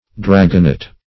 Dragonet \Drag"on*et\, n.